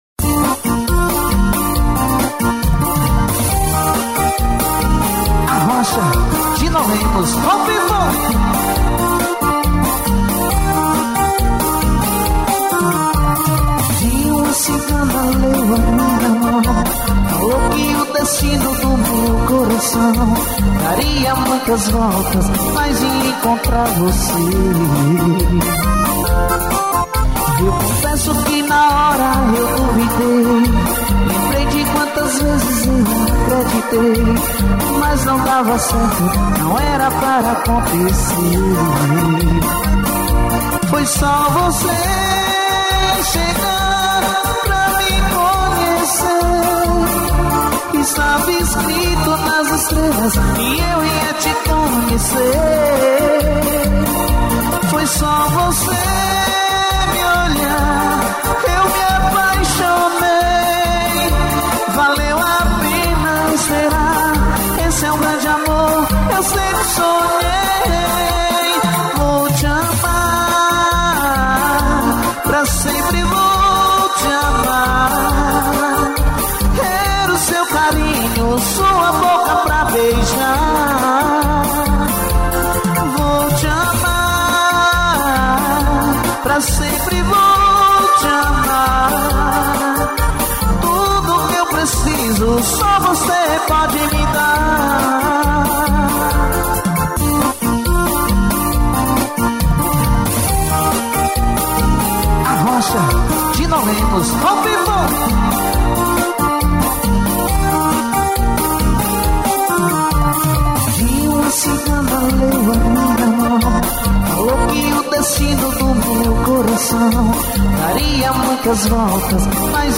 Sertanejo.